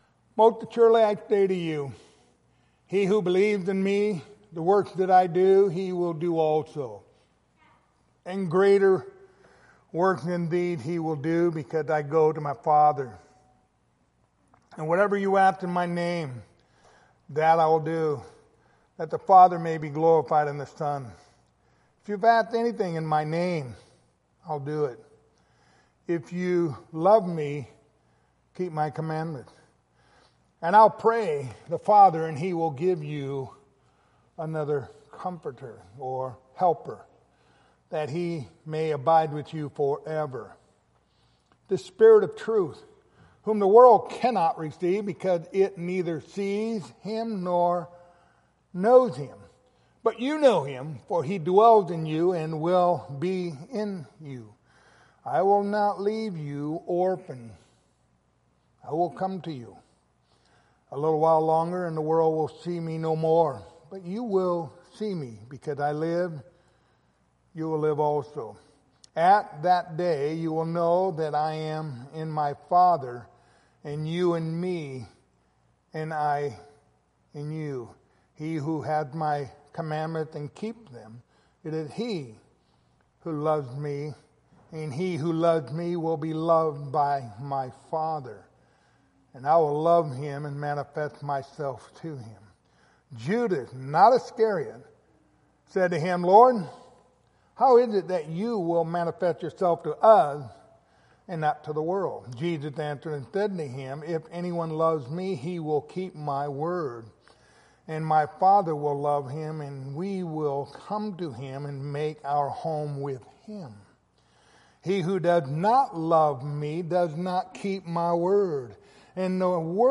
Passage: John 14:16-17 Service Type: Wednesday Evening